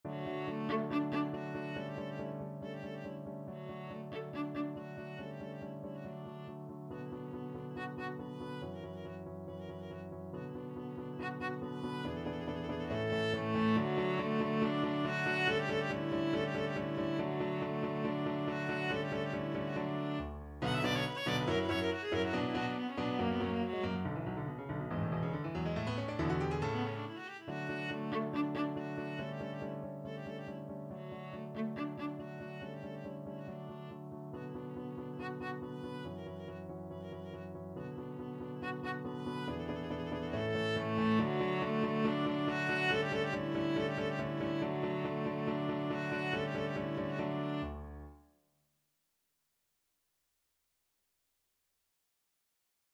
2/4 (View more 2/4 Music)
~ = 140 Allegro vivace (View more music marked Allegro)
Classical (View more Classical Viola Music)